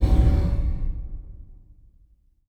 Fantasy Interface Sounds
Special Click 35.wav